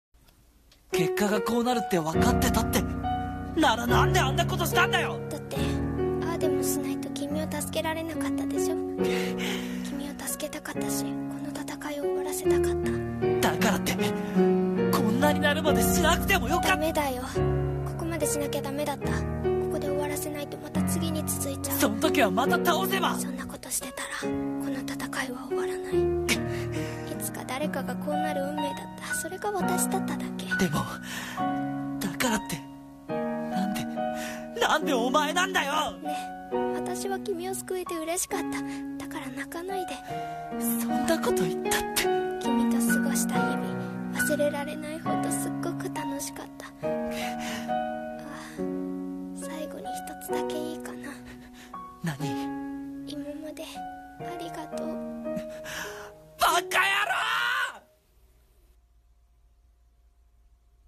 【声劇台本】